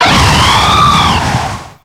Cri de Boréas dans sa forme Totémique dans Pokémon X et Y.
Cri_0641_Totémique_XY.ogg